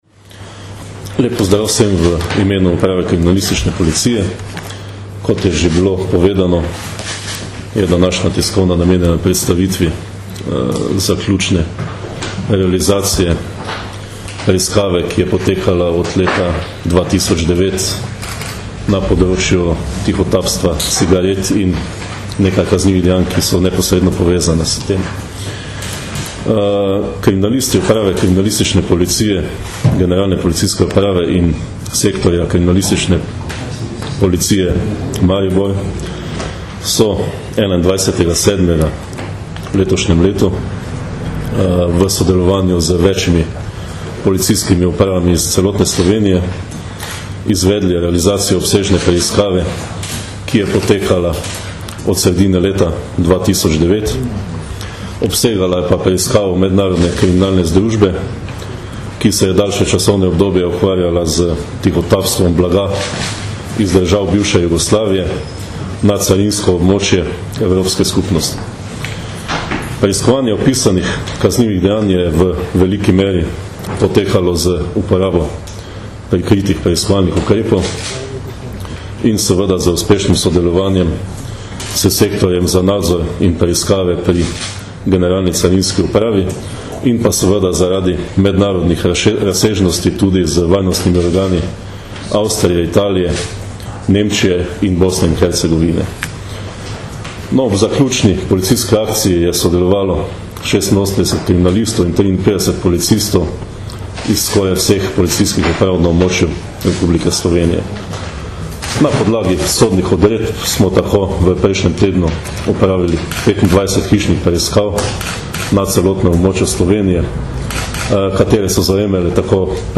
Kriminalistična preiskava v sodelovanju s carino s področja organizirane in gospodarske kriminalitete - informacija z novinarske konference
Zvočni posnetek izjave Marjana Fanka (mp3)